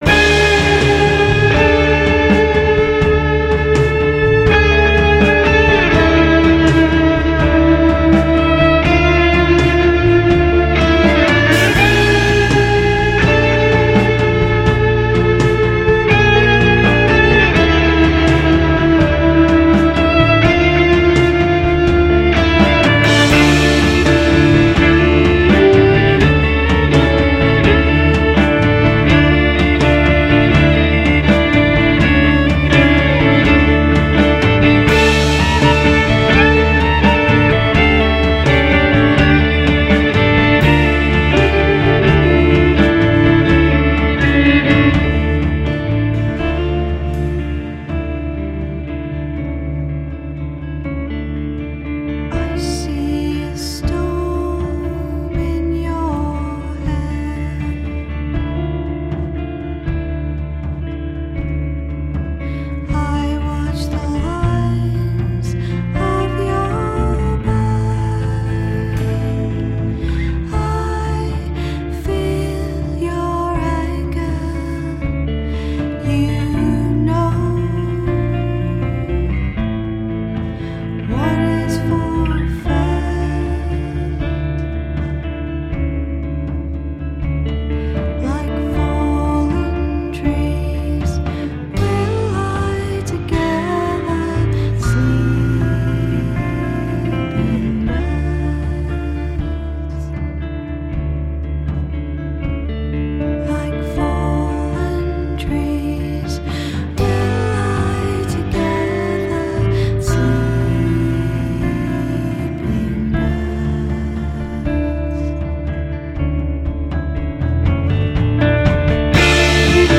You sound like you have a lot of folk music influences?